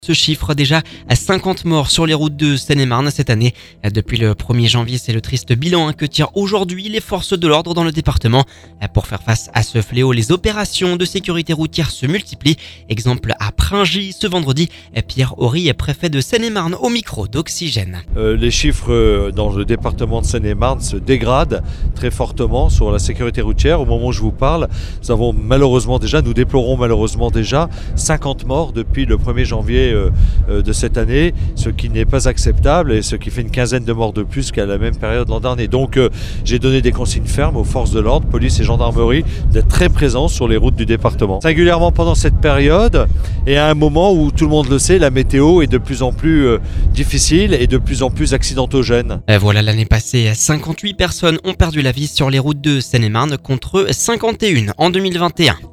Pierre Ory, préfet de Seine-et-Marne au micro d’Oxygène…